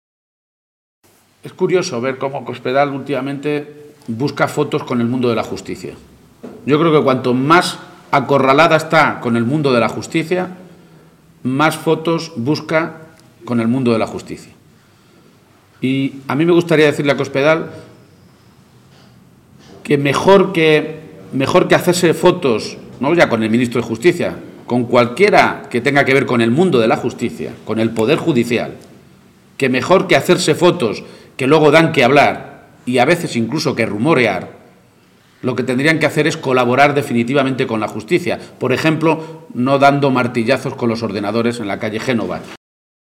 García-Page se pronunciaba de esta manera esta mañana, en una comparecencia ante los medios de comunicación en la que insistía en que el episodio de este fin de semana ha vuelto a poner de manifiesto «lo que de verdad le interesa a Cospedal, y a lo que en realidad ha estado dedicado estos cuatro años: A los líos internos del PP».